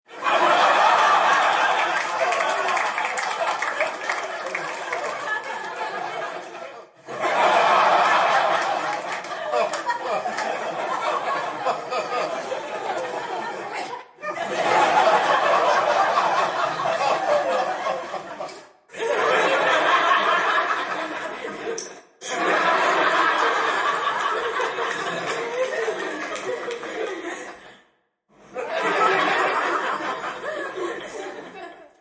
Download Comedy sound effect for free.
Comedy